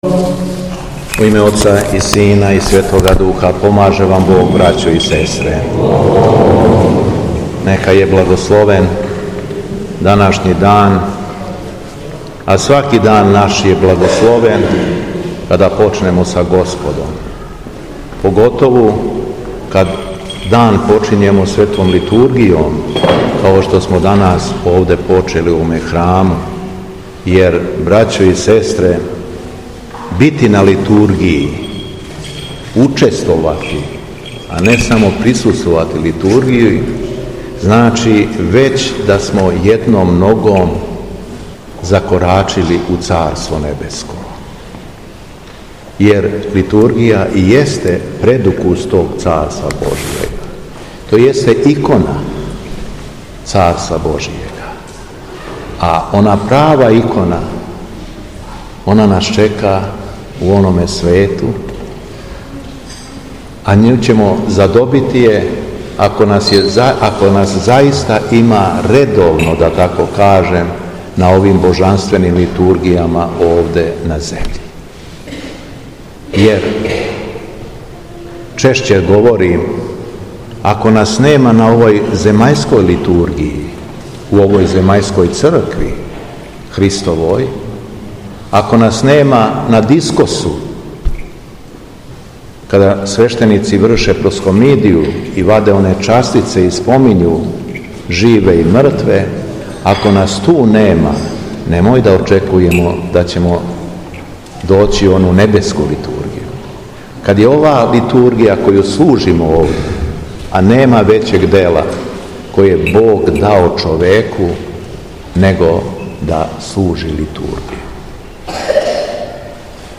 У недељу 14. септембра 2025. године, када Црква прославаља Преподобног Симеона Столпника и Начало индикта, Његово Високопреосвештенство Митрополит шумадијски Господин Јован служио је архијерејску Литургију у храму Светог великомученика Георгија у Дрену, надомак Лазаревца.
Беседа Његовог Високопреосвештенства Митрополита шумадијског г. Јована